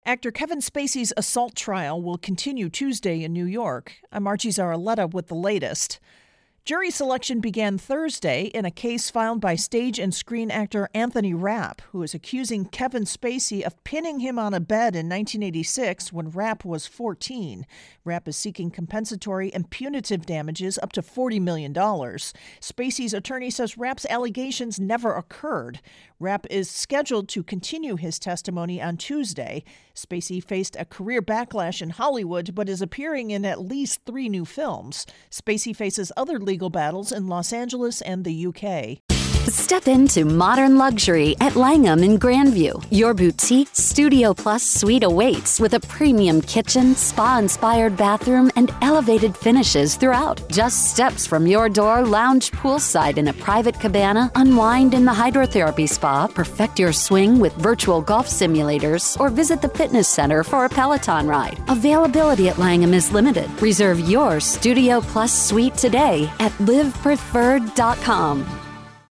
EXPLAINER: A look at the Kevin Spacey-Anthony Rapp trial
AP correspondent